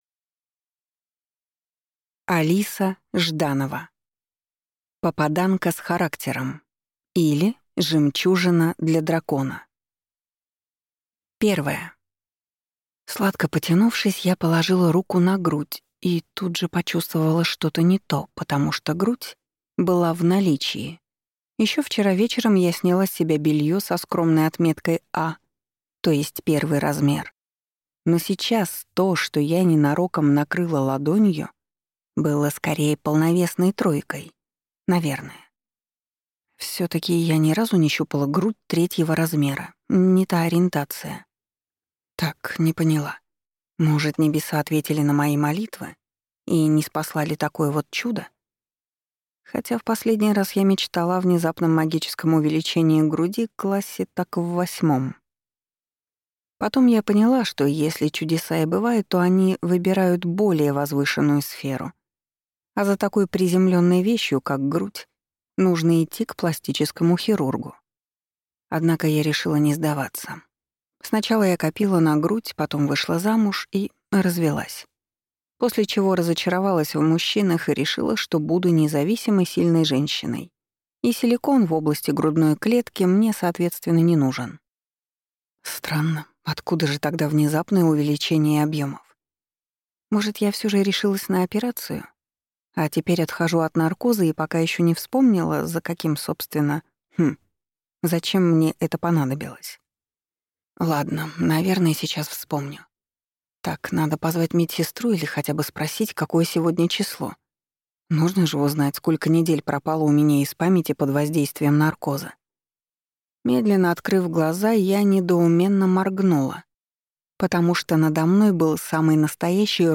Аудиокнига Попаданка с характером, или жемчужина для дракона | Библиотека аудиокниг